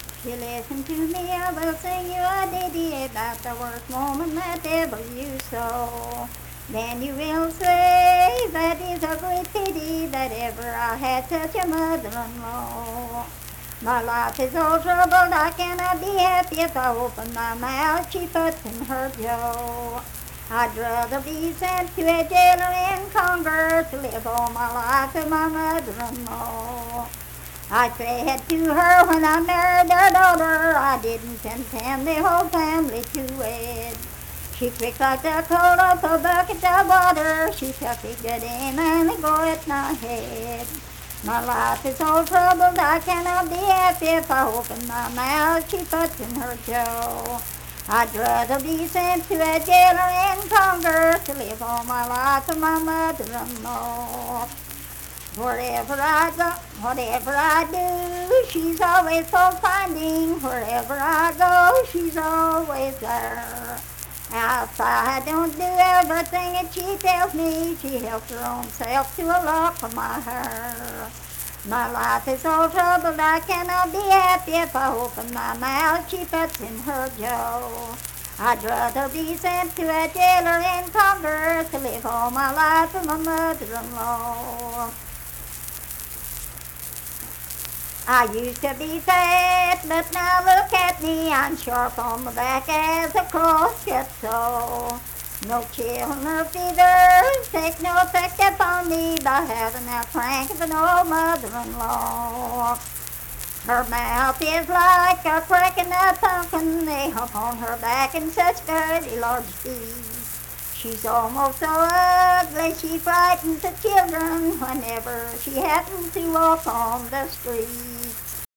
Unaccompanied vocal music performance
Verse-refrain 5(4) & R(4).
Voice (sung)